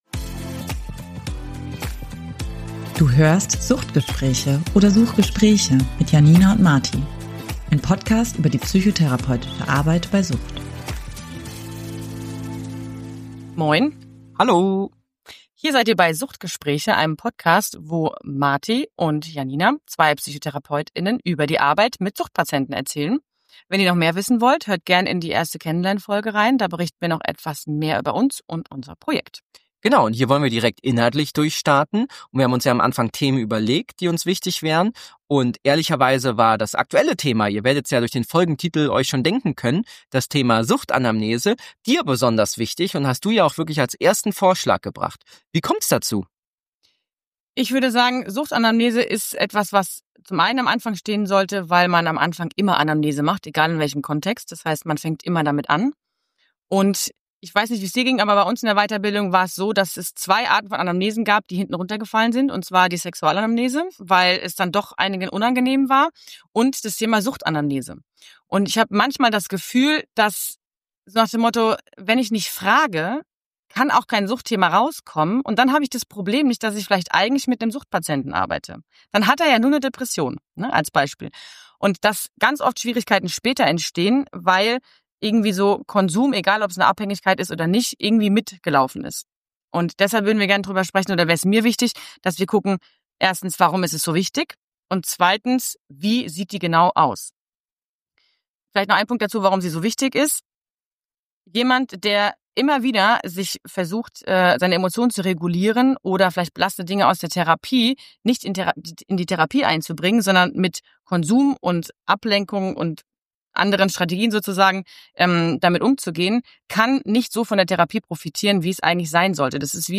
So stellen wir fest, dass diese in der Fort- und Weiterbildung häufig vernachlässigt wird, obwohl eine gute Suchtanamnese aus vielerlei Gründen enorm wichtig für den Behandlungsprozess, die Diagnostik, aber auch die therapeutische Beziehung ist. Neben bewährten Strategien bei der Anamnese und konkreten Vorgehensweisen bei Hindernissen steht in der Folge auch ein Rollenspiel im Vordergrund, bei wir eine Suchtanamnese einmal komplett durchspielen.